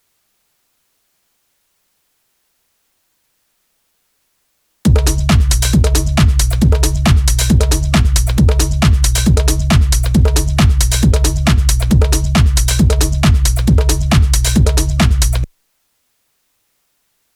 Noise on Analog Heat drives me crazy
This is an Audio Example and it is limited in Ableton with -1 db Ceiling and ca. 3db Gain Reduction.